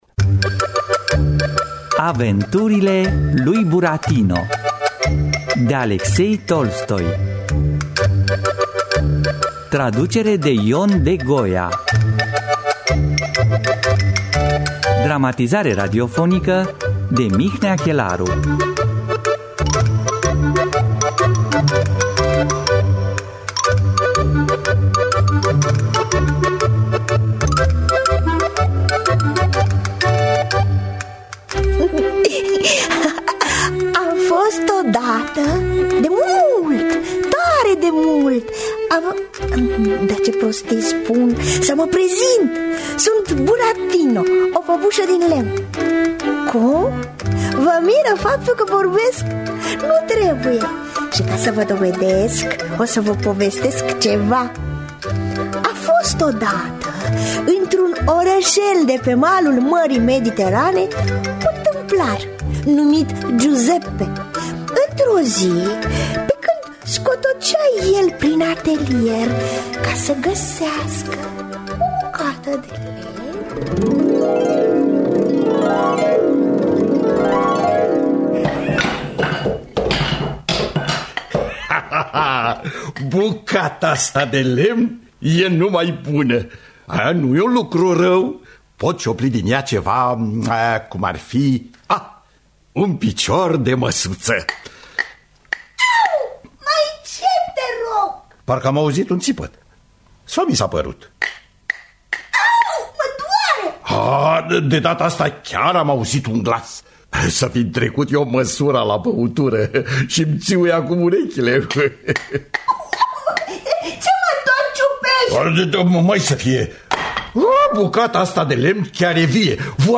Aventurile lui Burattino de Aleksei Tolstoi – Teatru Radiofonic Online